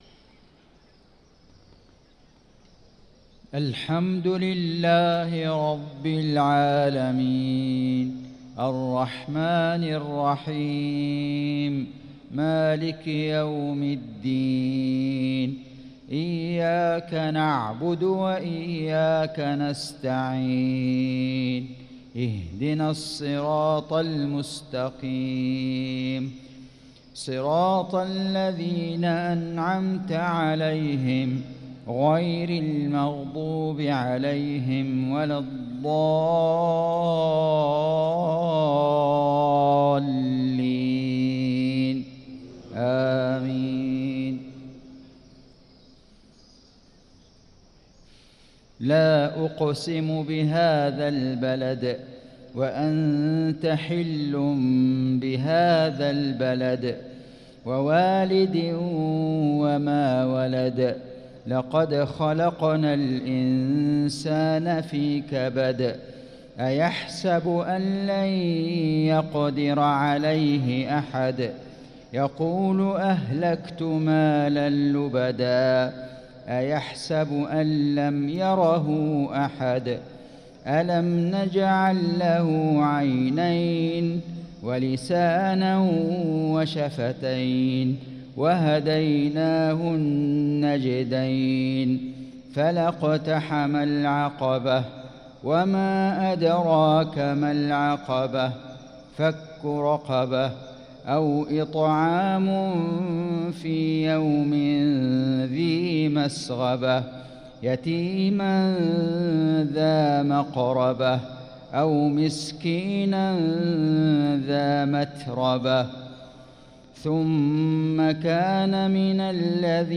صلاة المغرب للقارئ فيصل غزاوي 3 رمضان 1445 هـ
تِلَاوَات الْحَرَمَيْن .